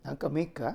Aizu Dialect Database
Type: Yes/no question
Final intonation: Rising
Location: Showamura/昭和村
Sex: Male